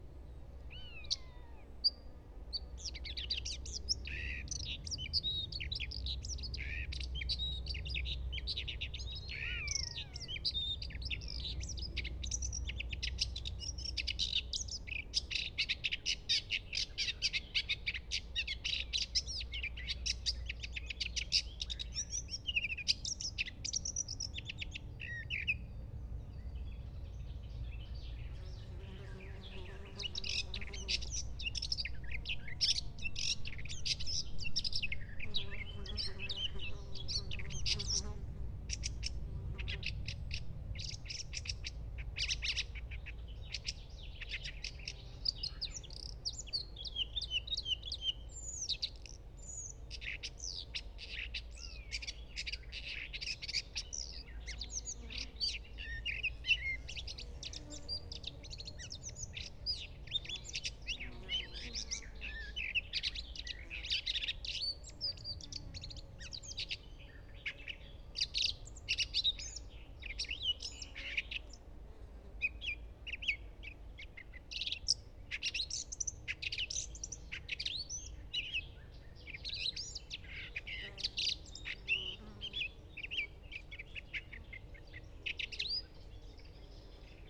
Red List Thirty Nine – Red-Backed Shrike
I’m sure you’d love to hear what a Red-backed Shrike sounds like (just in case one turns up in the garden and starts impaling things), so here you go…it’s surprisingly twittery!